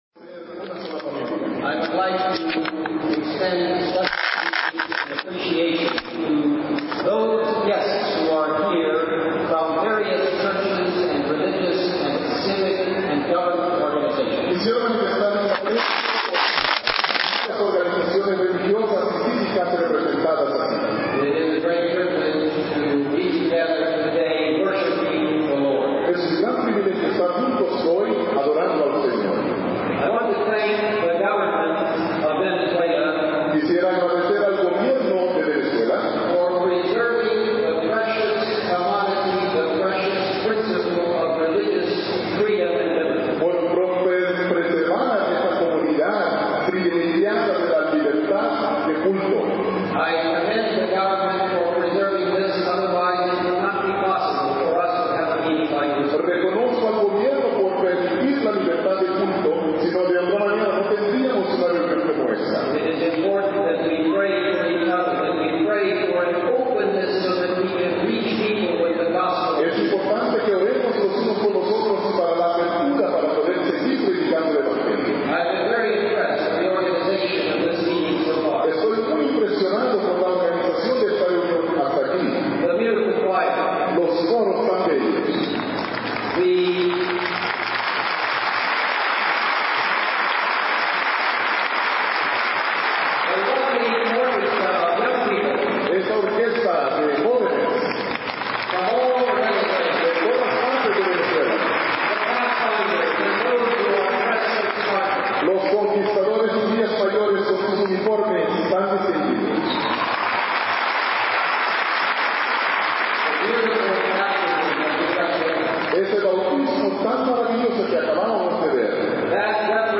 Sermones
sermon del pr ted wilson en la celebracion del centenario adventista en vzla.